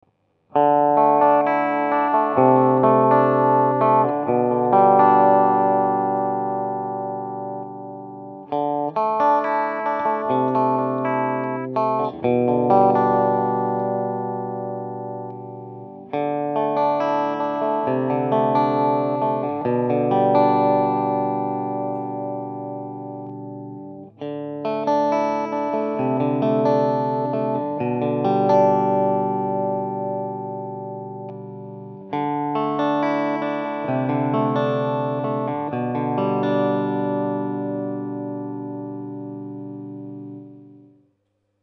Гиър: китара Фендер Страт->усилвател Гарланд 20/20->кабинет Маршал 1936->микрофон Бери C1
Няма никаква допълнителна обработка.
Vol-5, Pres-0 и 5позиционния суич на китарата: